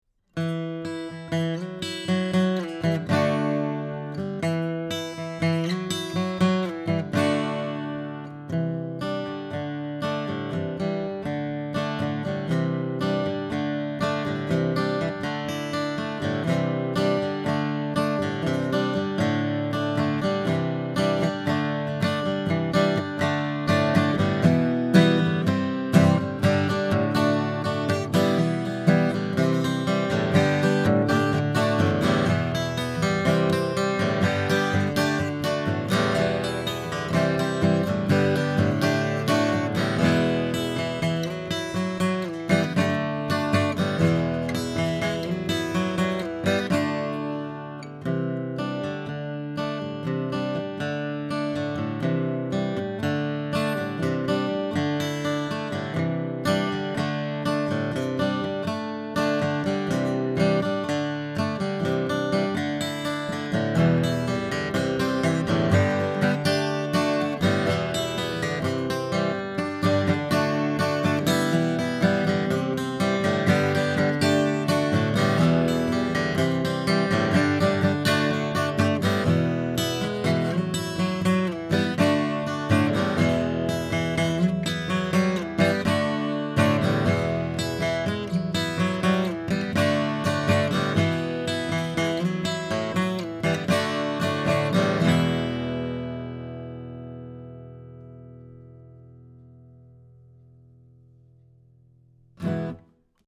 Here are 33 quick, 1-take MP3s using this UM70 and M70 in a large room going into a Audient Black mic pre, into a Sony PCM D1 flash recorder, with MP3s made from Logic. These tracks are just straight signal with no additional EQ, compresson or effects:
UM70 CAPSULE TESTS